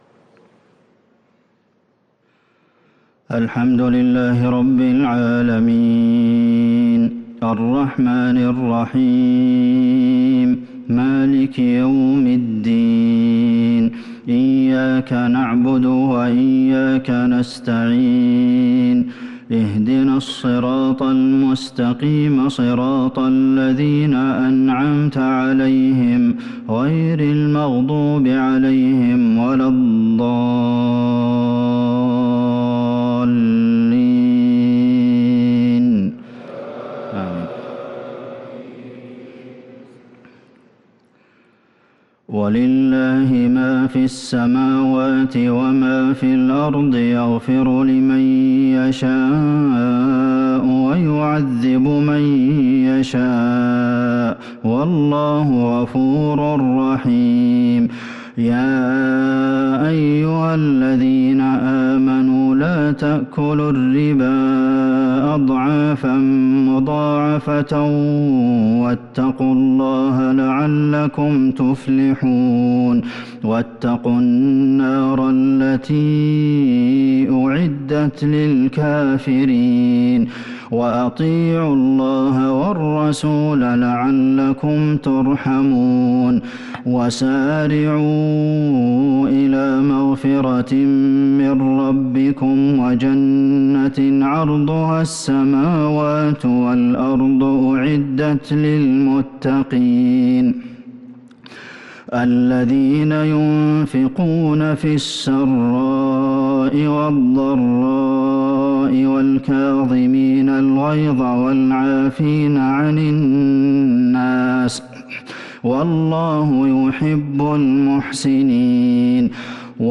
صلاة العشاء للقارئ عبدالمحسن القاسم 4 ذو الحجة 1443 هـ
تِلَاوَات الْحَرَمَيْن .